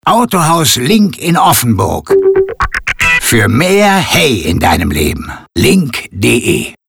Soud Logo